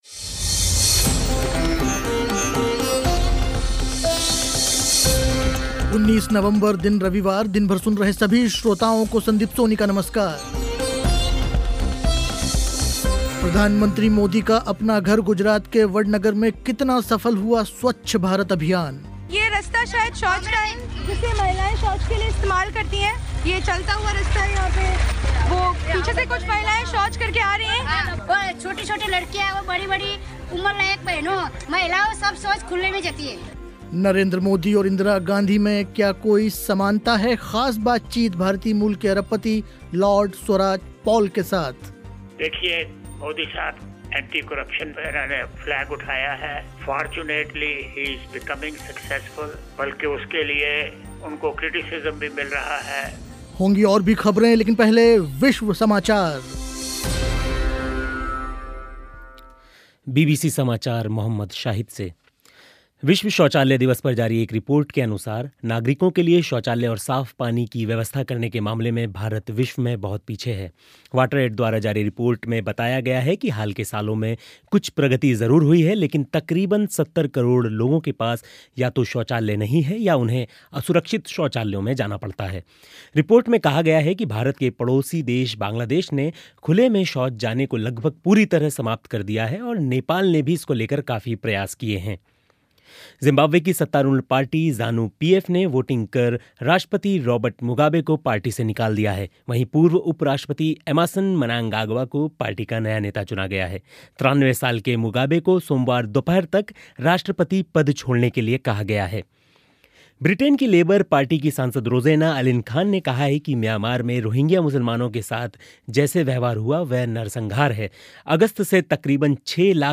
प्रधानमंत्री मोदी का अपना घर गुजरात के वडनगर में कितना सफल हुआ स्वच्छ भारत अभियान. नरेंद्र मोदी और इंदिरा गांधी में क्या कोई समानता है, ख़ास बातचीत भारतीय मूल के अरबपति लॉर्ड स्वराज पॉल के साथ.